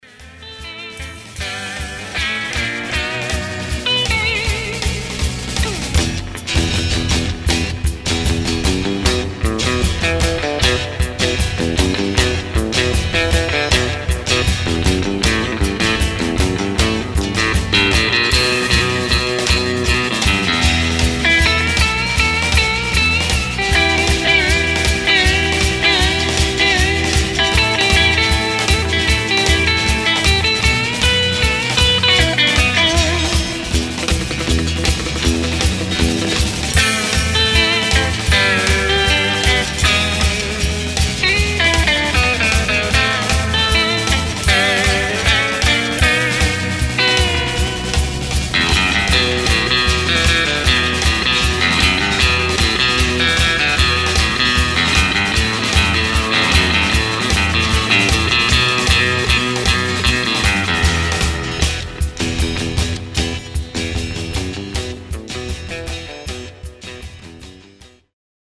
The sound is not very close, but maybe the groove is there.
It's during the same rehearsal day. I added some reverb.